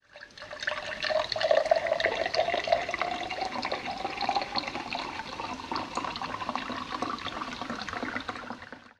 Wasserglas
Bei dem Hörbeispiel handelt es sich um das Einschenken von Mineralwasser in ein leeres Glas. Dabei entsteht das typisch plätschernde Geräusch des Wassers, welches sich mit dem zunehmenden Wasserpegel im Glas verändert.
wasserglas